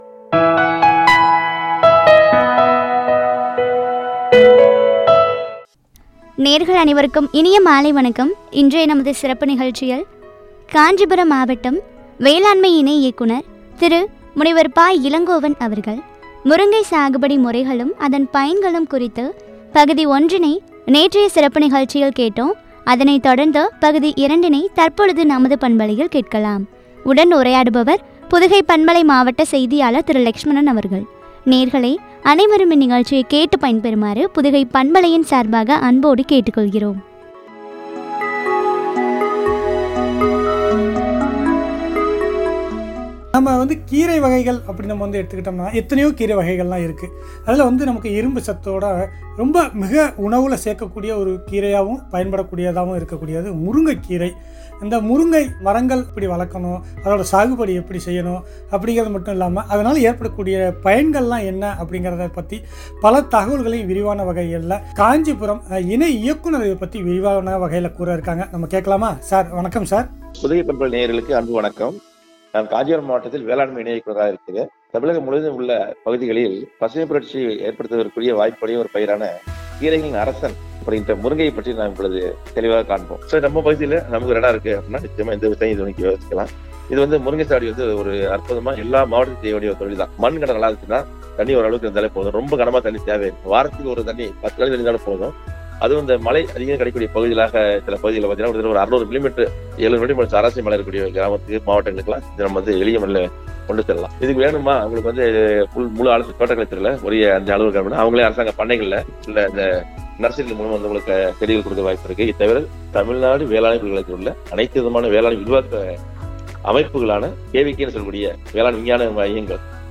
பற்றிய உரையாடல்.